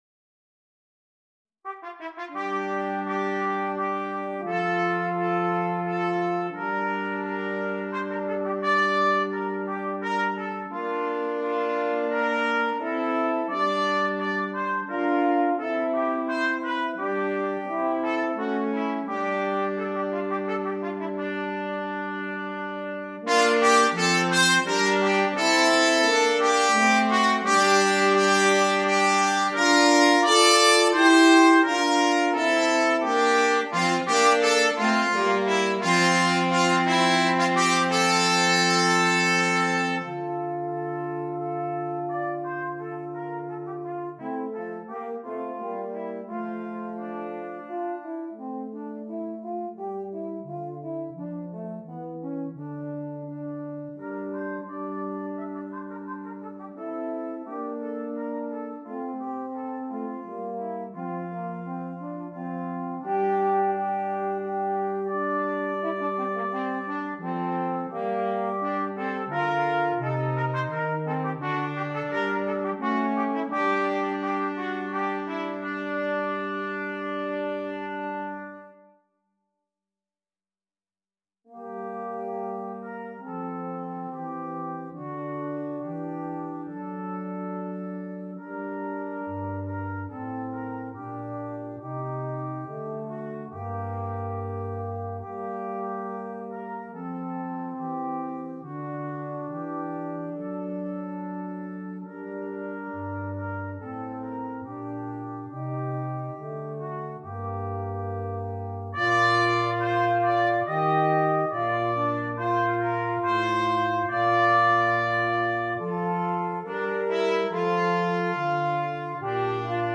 für Trompete, Horn und Posaune Schwierigkeit
Ensemblemusik für 3 Blechbläser PDF